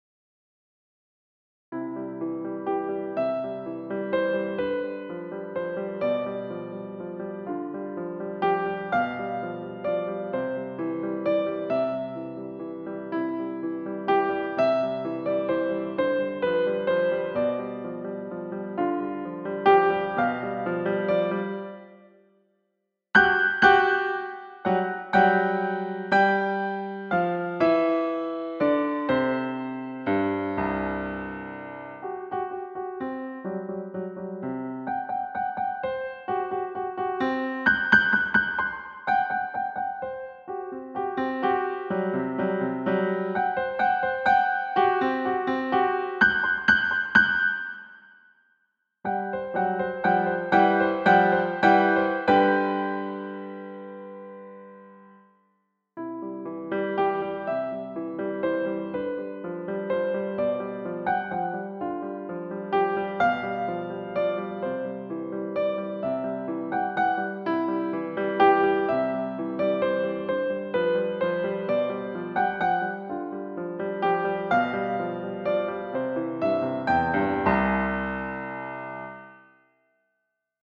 Piano sheet music composed by piano teacher.